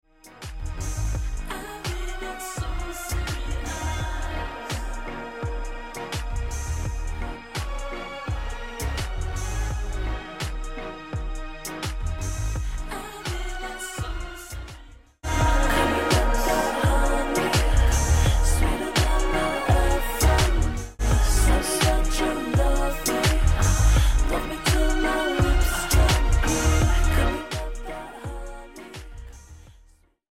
slowed w pitch